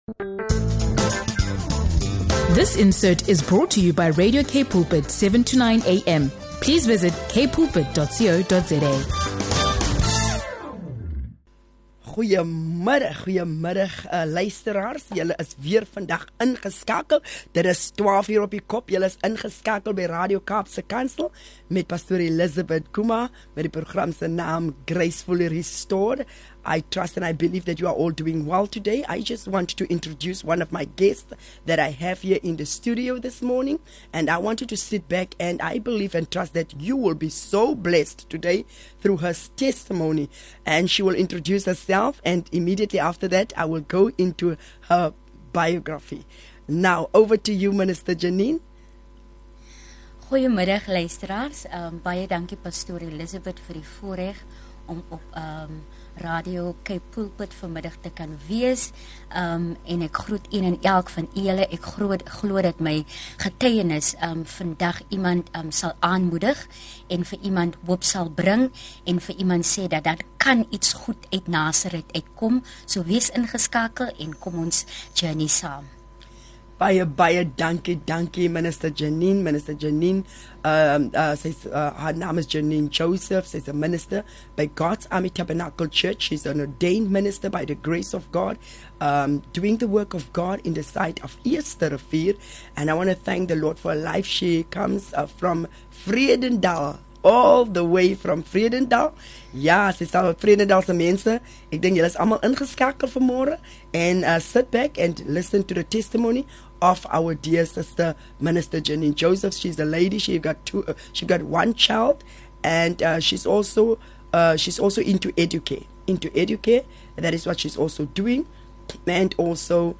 She encourages listeners to trust in God’s ability to restore the soul, reminding us that no matter the pain or brokenness we’ve faced, His grace brings renewal and transformation. This heartfelt conversation will inspire and uplift anyone seeking God’s healing touch in their life.